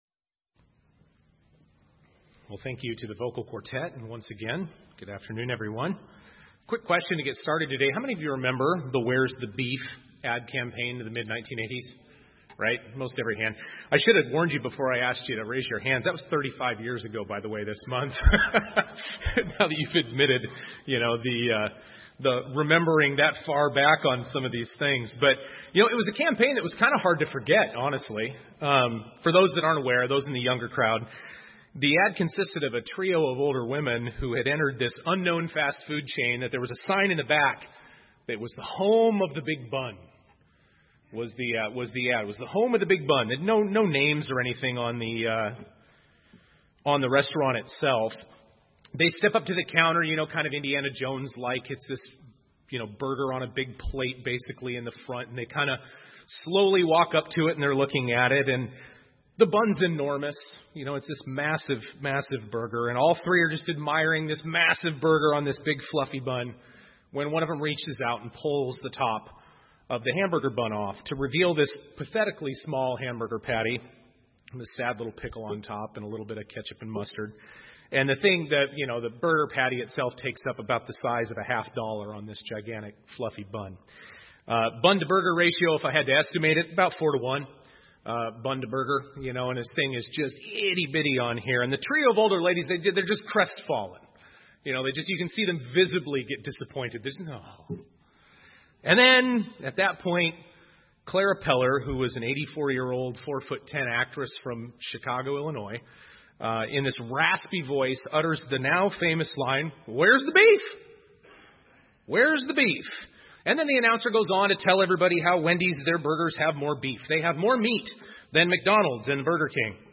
(Sound is fixed after about 15 seconds) In the mid-1980’s, Wendy’s released an ad campaign that went down in history as one of the top advertisements ever produced.